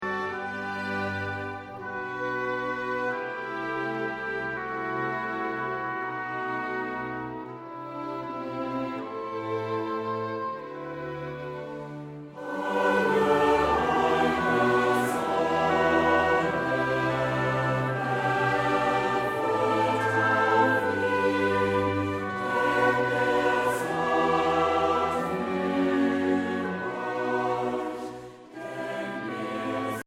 Trostvoll, harmonisch und warm